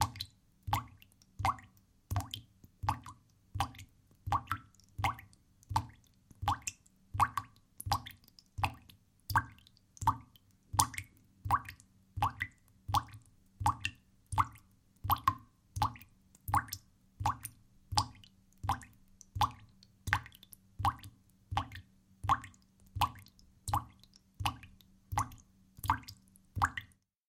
На этой странице собраны звуки слез — нежные, меланхоличные аудиозаписи падающих капель.
Здесь нет записей с плачущими людьми, только чистые звуки слезинок в высоком качестве.
Звуки слез: Это капают мои слезы от горя быстро